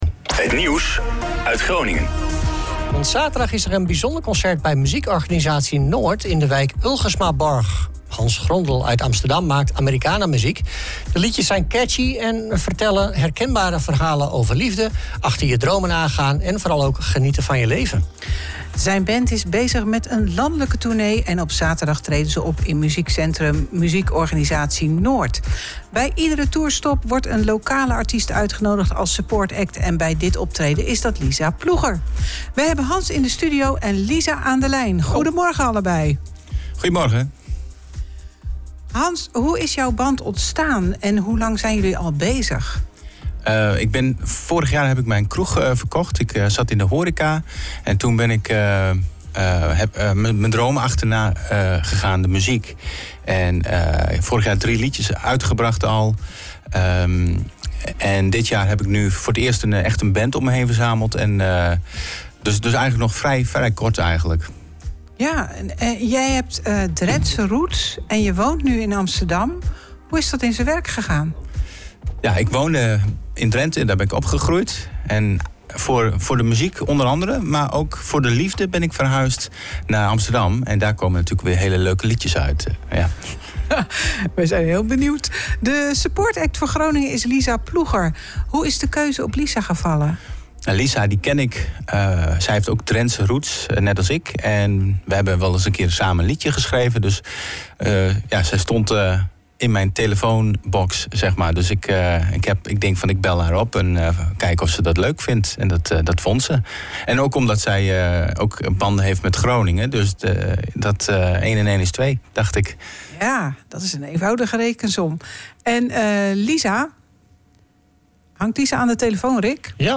Americana muziek
live in de studio